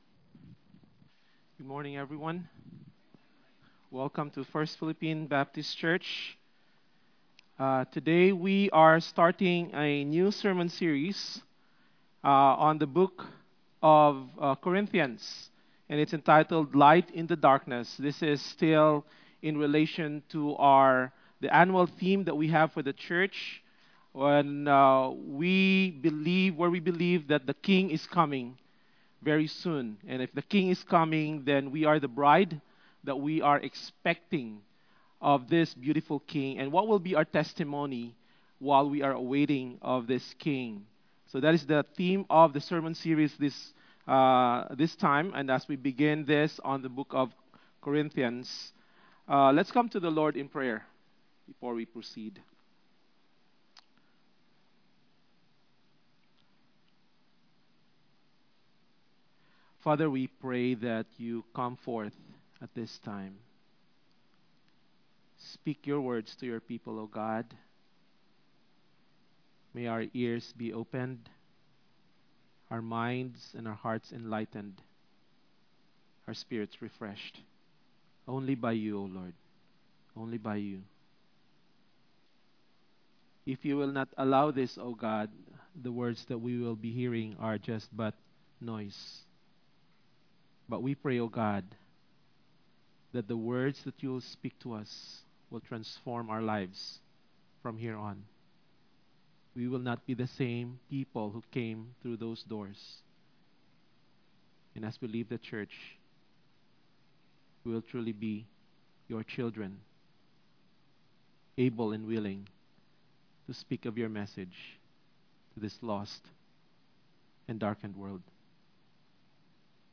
Sermons Archive - FPBC